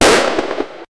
- Einführung von Soundeffekten für Bewegungen, Bomben, Raketen und Regenbogen-Animationen zur Steigerung des Spielerlebnisses.
bomb.wav